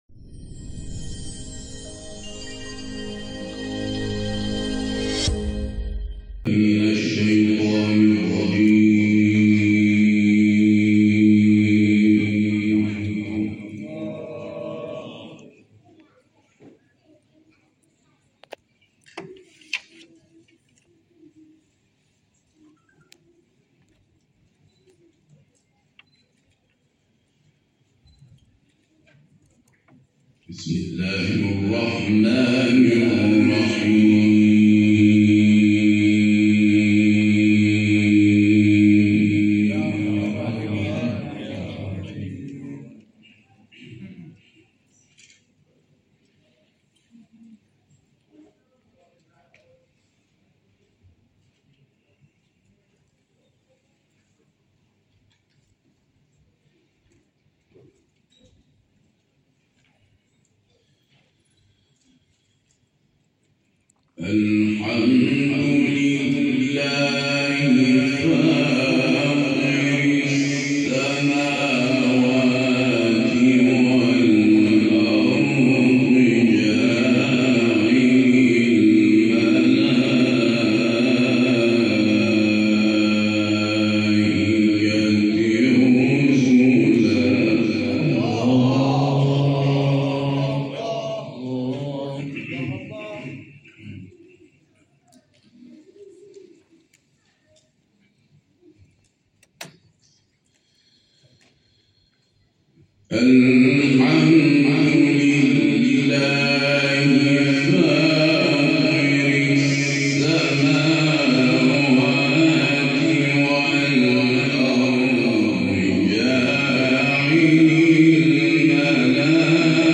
محفل انس با قرآن کریم شامگاه شنبه 17 آبان، با استقبال پرشور اعضای مجمع قاریان قرآن زابل، اساتید، دانشجویان و کارکنان دانشگاه زابل در مسجد بقیه‌الله الاعظم(عج) دانشگاه زابل برگزار شد.